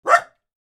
dog-wouf.mp3